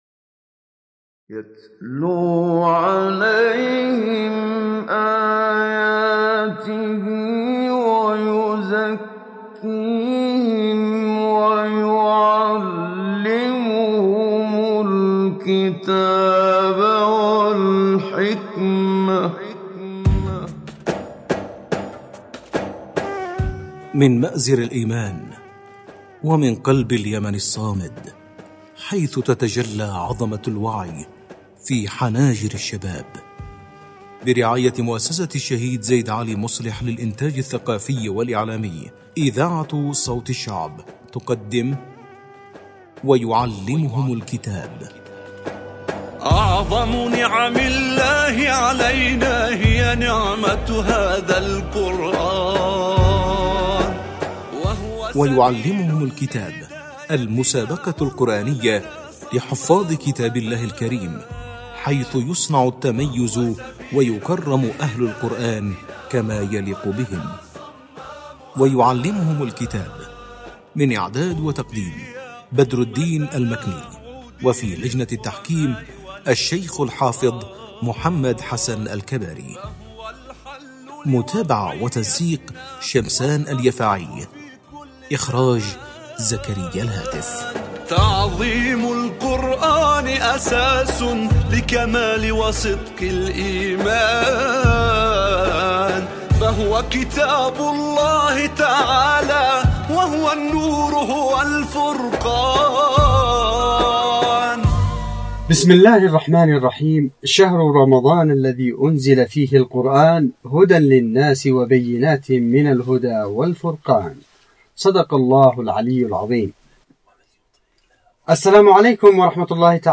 مسابقة_القرآن_الكريم_ويعلمهم_الكتاب_8.mp3